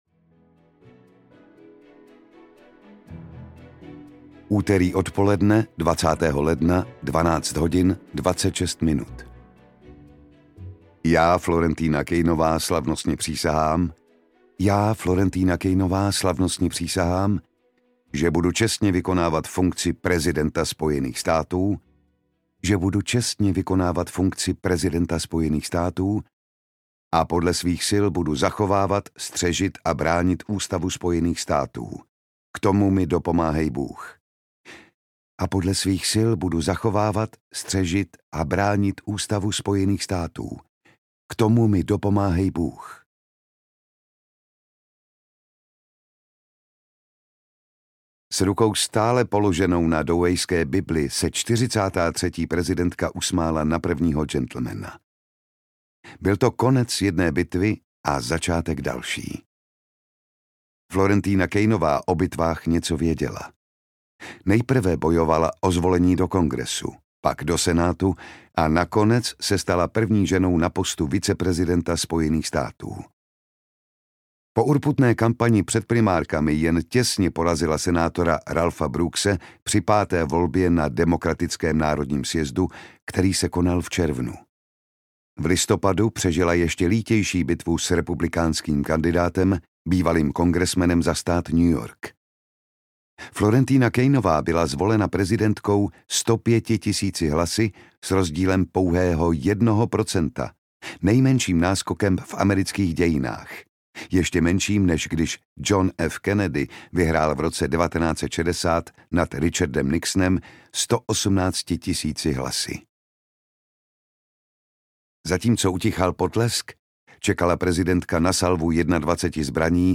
Povíme to prezidentce? audiokniha
Ukázka z knihy
• InterpretJan Šťastný